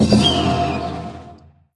Media:RA_Bo_Evo.wav UI音效 RA 在角色详情页面点击初级、经典和高手形态选项卡触发的音效